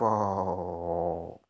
004_long_weird.fwonk.wav